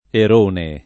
Erone [ er 1 ne ]